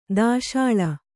♪ dāśāḷa